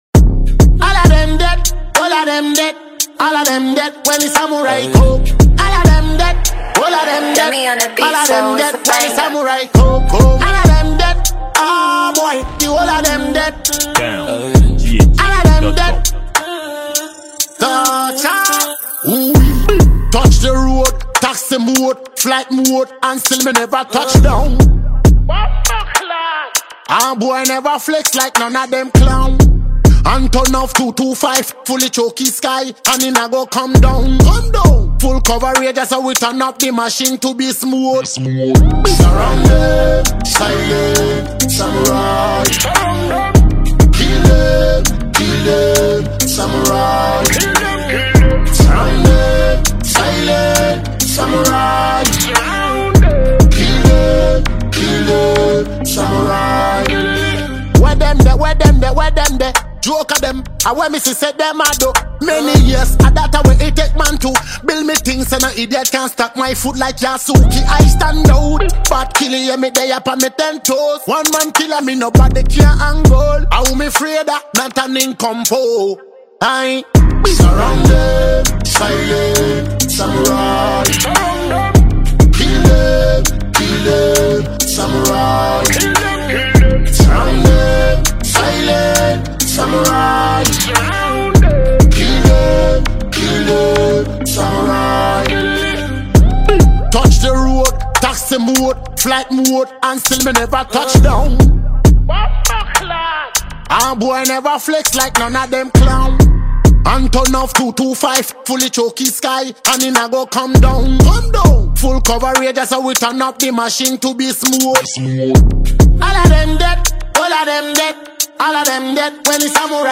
afrobeat reggae dancehall